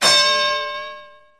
Cloche de Boxe Ring
cloche-de-boxe-ring.mp3